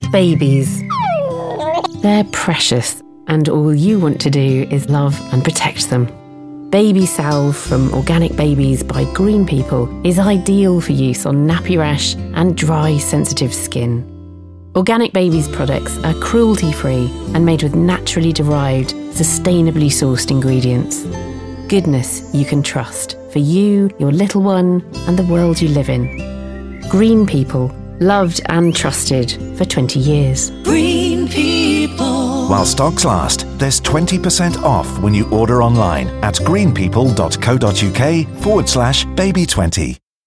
Green People Commercial September 2017